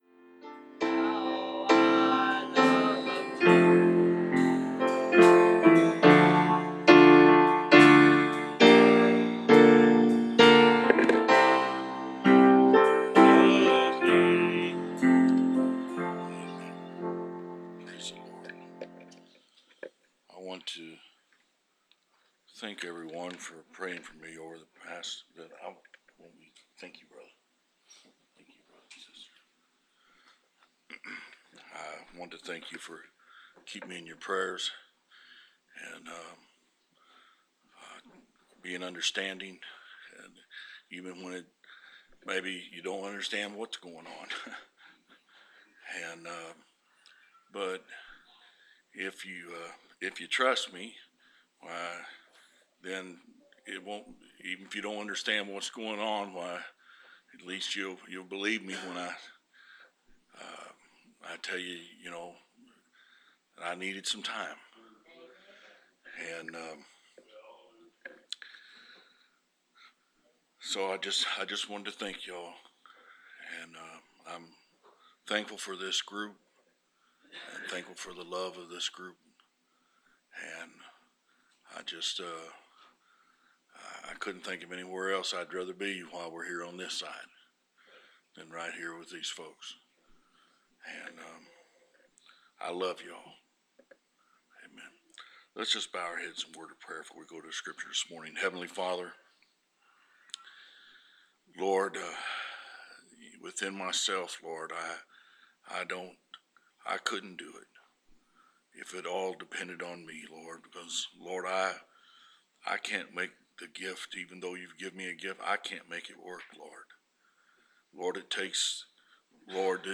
Preached August 28, 2016